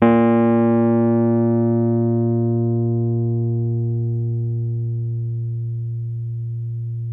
RHODES CL06R.wav